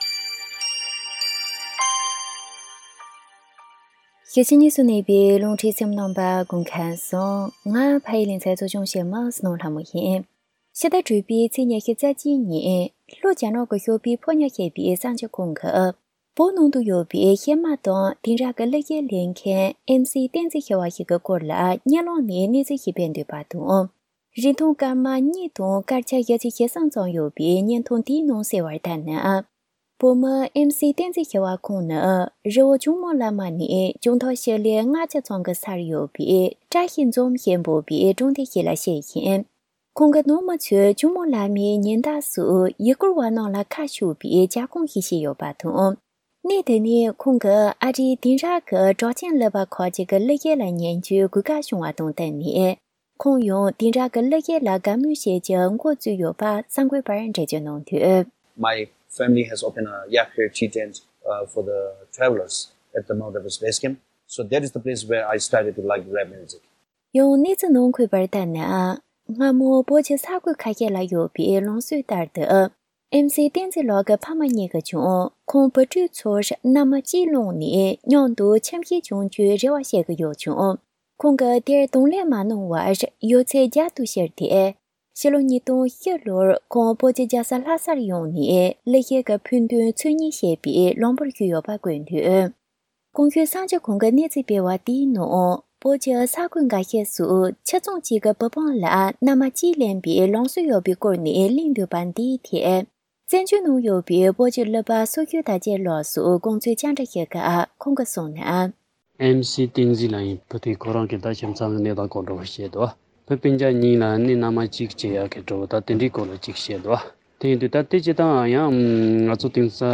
གནས་ཚུལ་བཅར་འདྲི